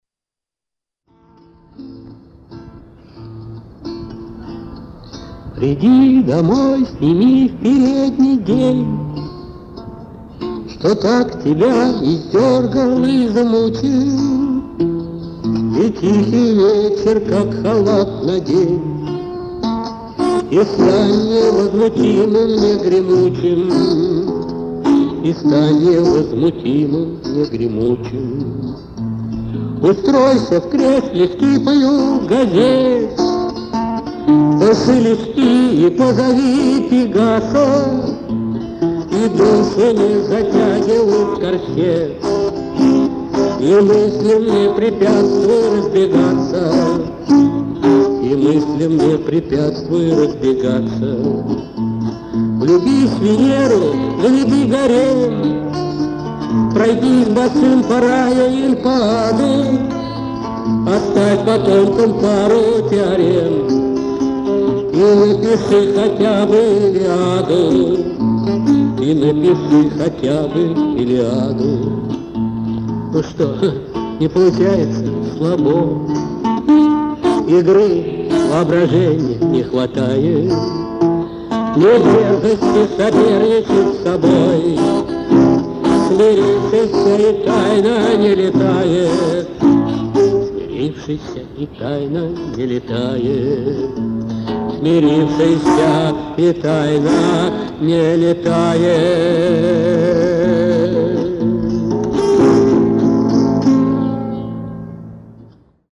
вокал, гитара
Философский романс